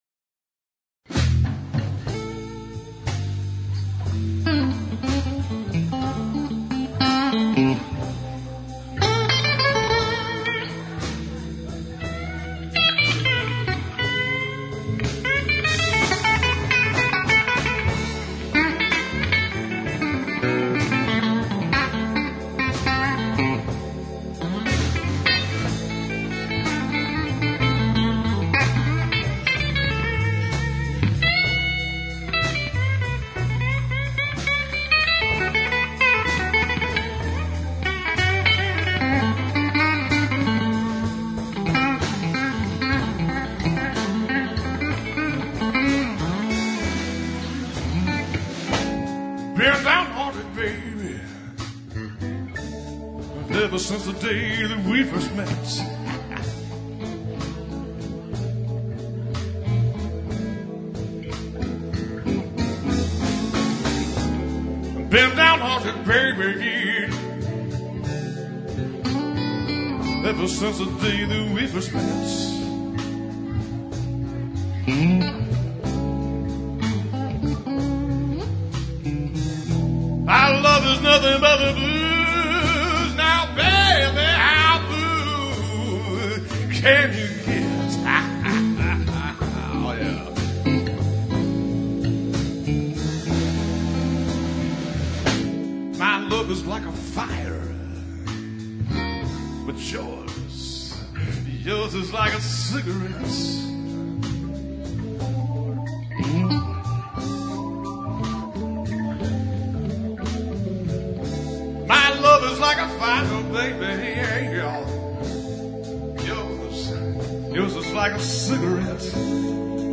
Songs Archive from the Defunct Grass Flats Blues Jam